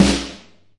描述：圈套，鼓套装tama dw ludwig打击乐打击样本鼓
标签： 路德维希 小鼓 DW 撞击声 样品 试剂盒 多摩 打击
声道立体声